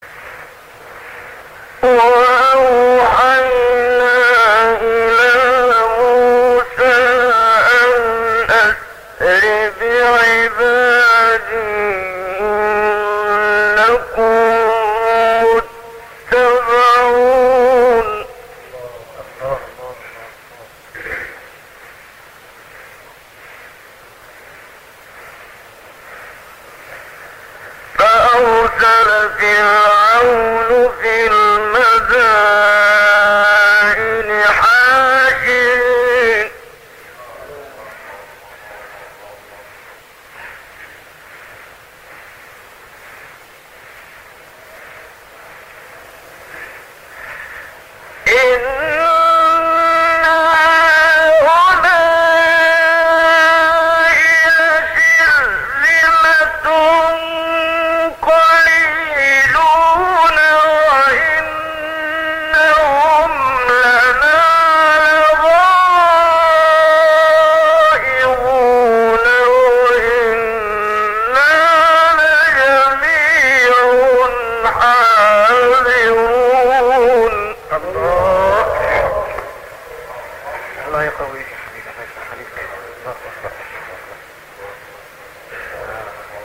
سایت-قرآن-کلام-نورانی-منشاوی-صبا-2.mp3